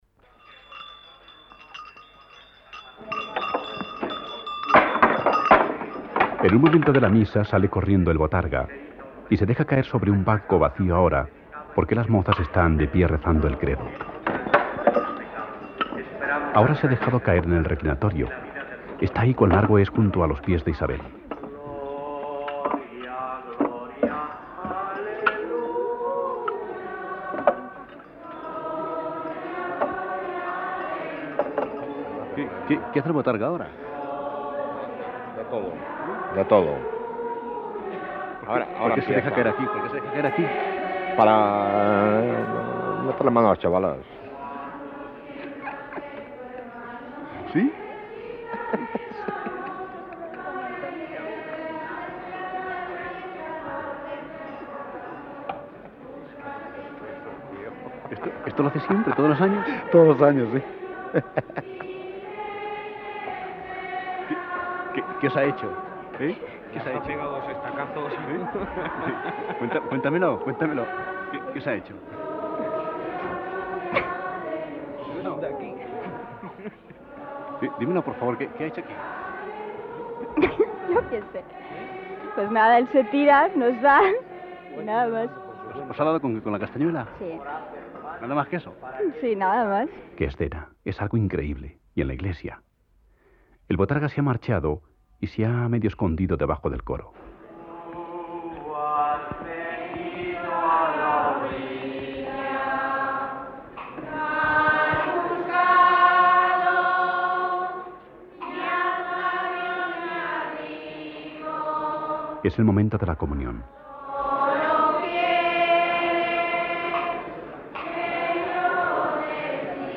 El momento cumbre, tras recorrer el pueblo pidiendo, de casa en casa, tiene lugar en el interior de la iglesia con las danzas y la actuación del botarga que, según dicen, se está pasando tres pueblos pero, eso, todo ello, es el contenido de este programa, grabado en enero del año 1987, para la serie “Mi Tierra, mi Gente” de Radio Nacional de España Tags: Raíces del Liberal